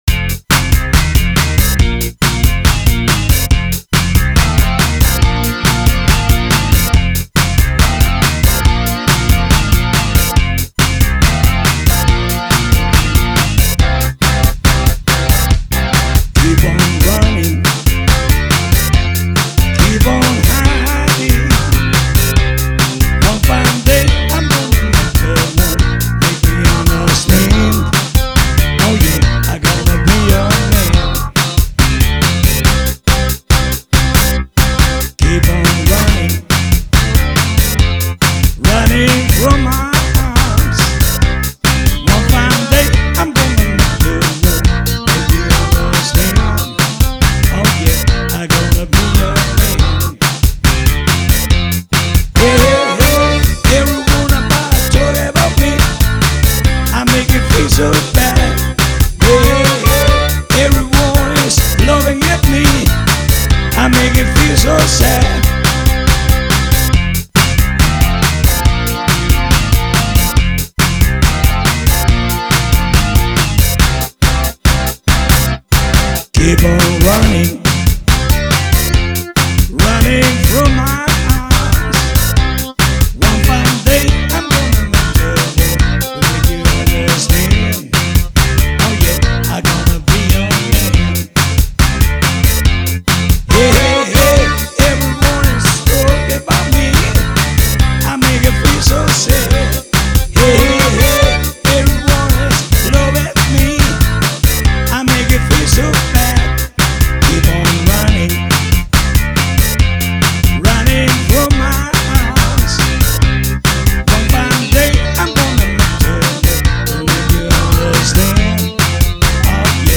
rocking